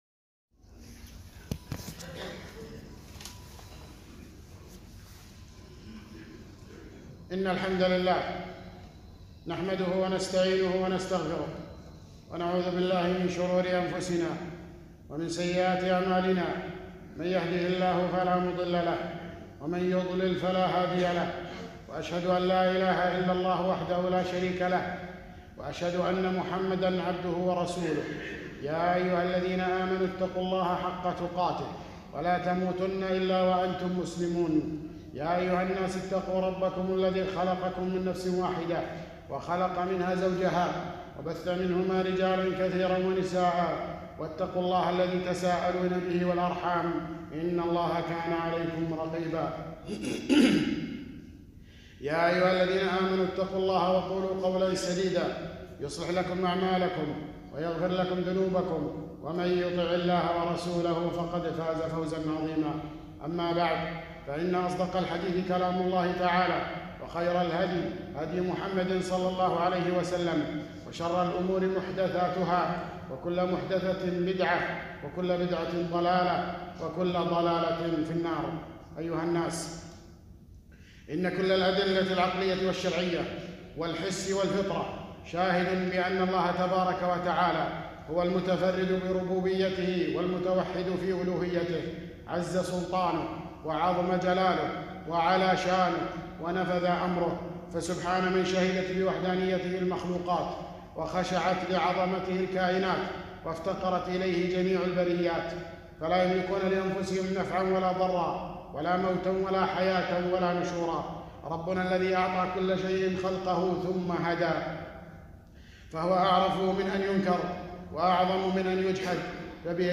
خطبة - التحذير من الإلحاد المعاصر